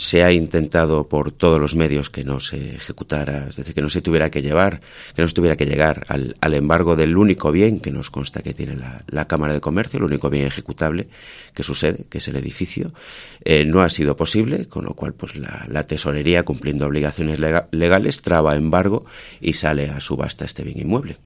El subdelegado del Gobierno en Ávila, Arturo Barral, ha asegurado en Mediodía Cope, que “se ha intentado por todos los medios no llegar al embargo del edificio de la Cámara de Comercio de Ávila”.